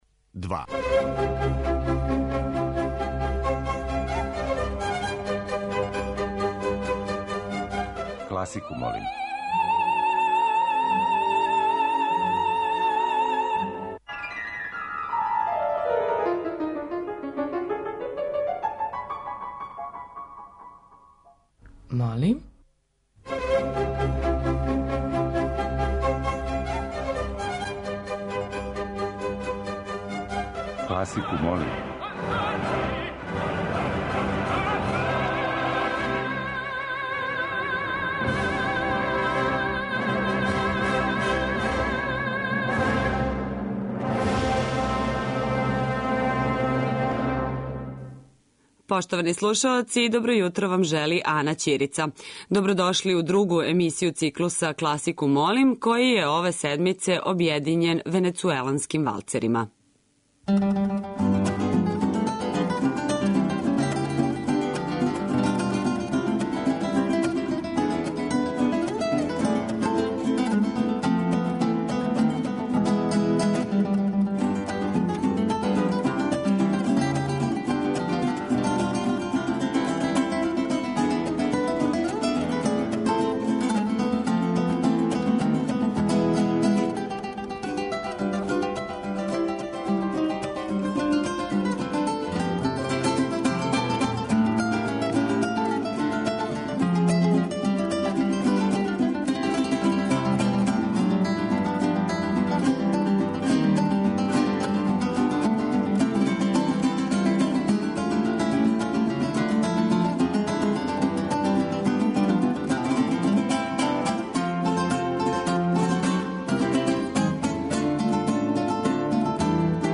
Листа предлога за топ-листу класичне музике Радио Београда 2 ове седмице је обједињена венецуеланским валцерима.
Уживо вођена емисија, окренута широком кругу љубитеља музике, разноврсног је садржаја, који се огледа у подједнакој заступљености свих музичких стилова, епоха и жанрова. Уредници (истовремено и водитељи) смењују се на недељу дана и од понедељка до четвртка слушаоцима представљају свој избор краћих композиција за које може да се гласа телефоном, поруком, имејлом или у ФБ групи.